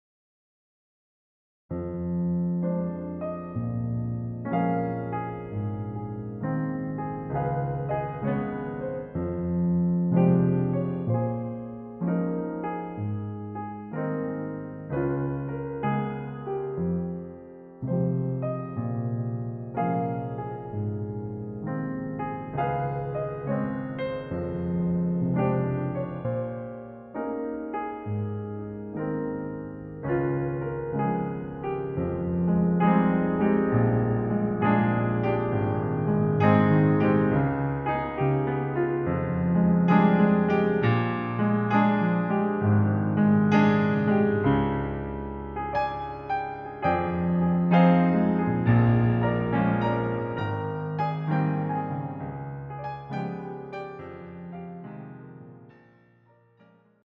• Jazz Cocktail Covers